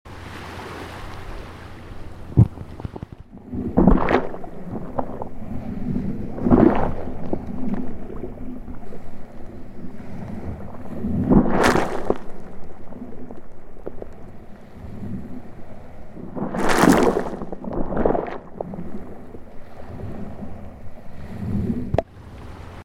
Like… a log. On a beach. Being kissed by waves.
I stabbed - well, gently poked - this poor log with a needle-tipped contact mic adapter I made - because that’s what you do when you love sound. Turns out, driftwood is an excellent acoustic storyteller. Especially when the ocean’s doing half the work.